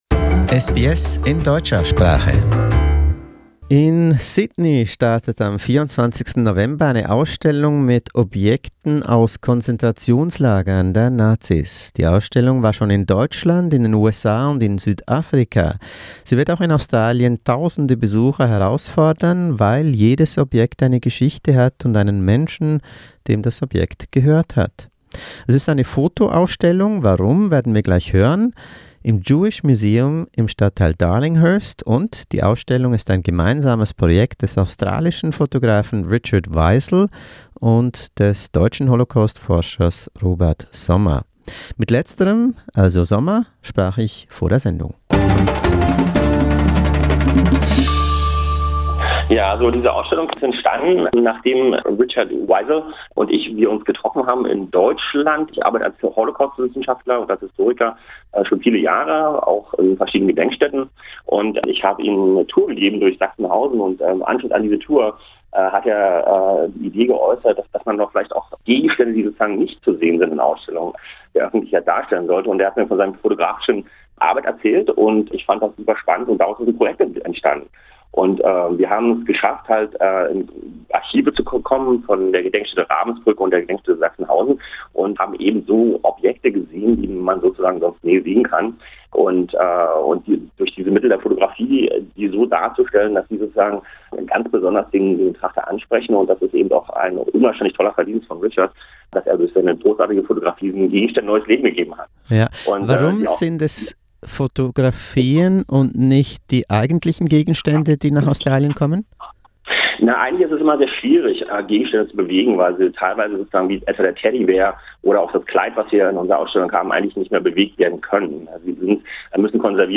An interview.